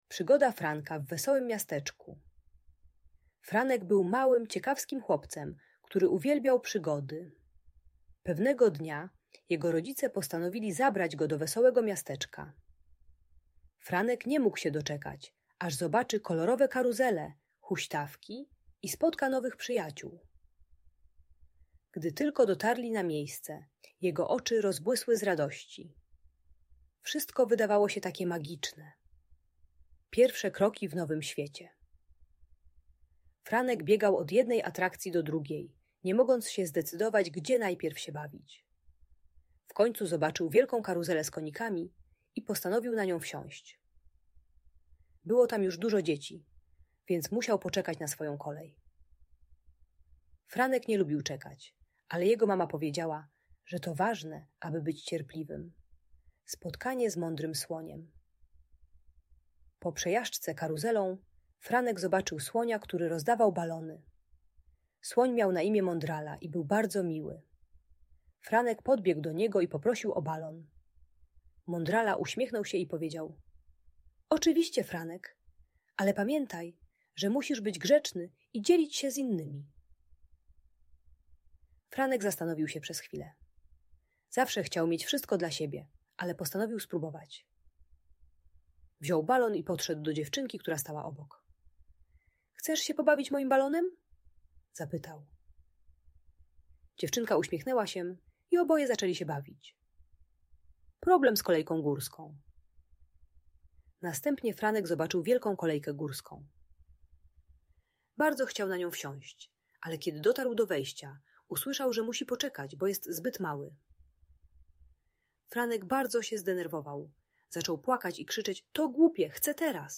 Przygoda Franka w Wesołym Miasteczku - story - Audiobajka